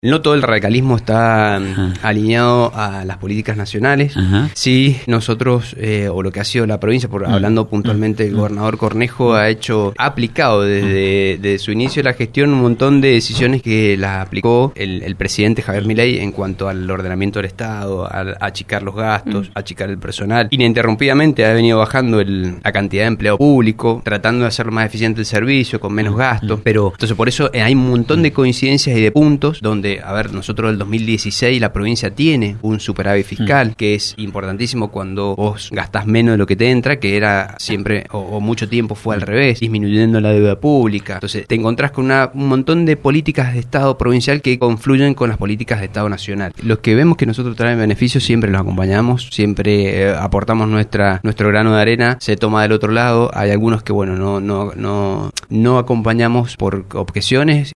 Las declaraciones surgieron durante una visita a LV18 donde habló de diversos temas relacionados con la actualidad nacional, provincial y local.